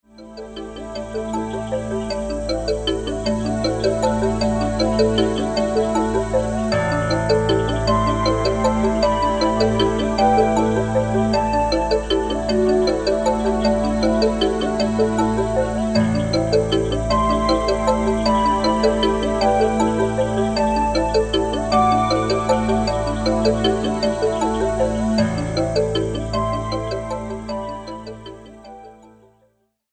インストを中心にコーラスも織り交ぜた計5曲、20分程度のオリジナル曲を収録。
「聞き込む音楽」というよりは、さりげなく部屋で聞く音楽を目指しました。
ジャンル： EasyListening, NewAge